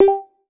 Synth Double Hit.wav